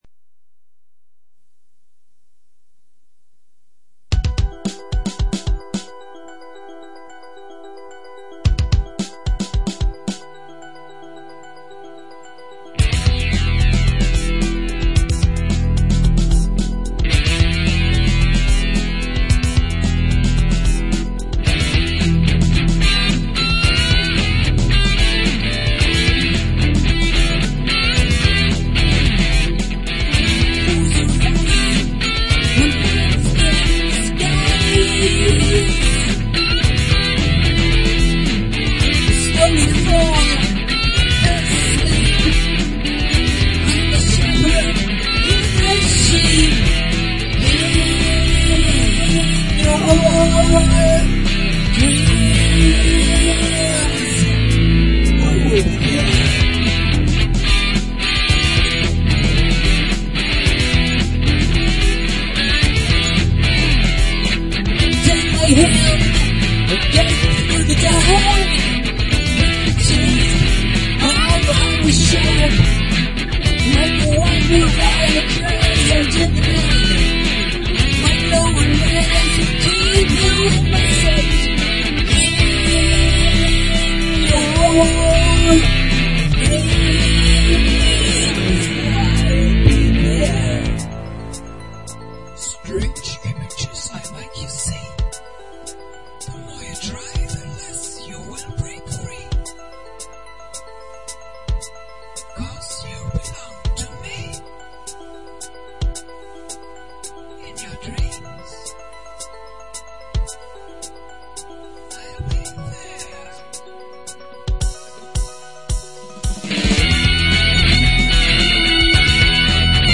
TypLP (Studio Recording)